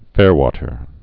(fârtər, -wŏtər)